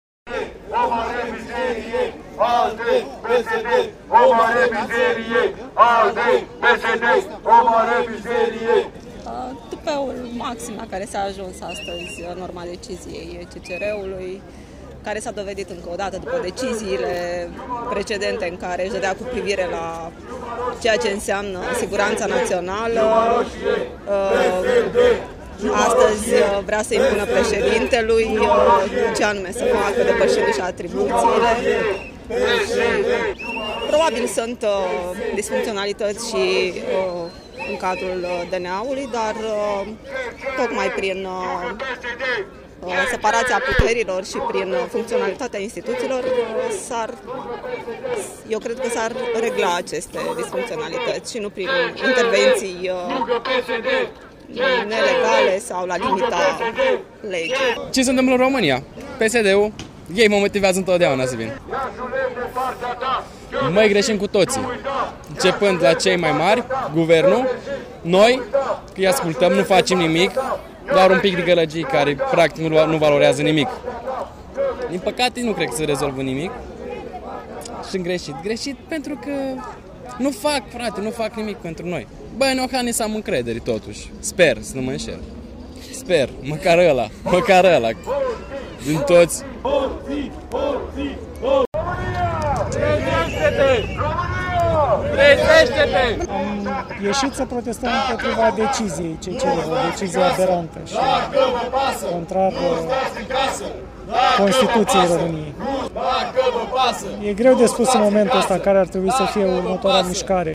În jur de  o sută de ieșeni au protestat în Piata Unirii împotriva deciziei Curții Constituționale care îl obligă pe presedinte sa o demita de șefa DNA. Protestatarii au strigat „ALDE si cu PSD, tradatori de patrie”, „PSD partid de hoti, ticalosi si mafioti”, „Hotii la inchisoare, nu la guvernare”, „Jos Tariceanu, Dragnea si Dorneanu.
31-mai-proteste.mp3